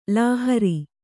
♪ lāhari